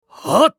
男性
クール系ボイス～戦闘ボイス～
【攻撃（強）1】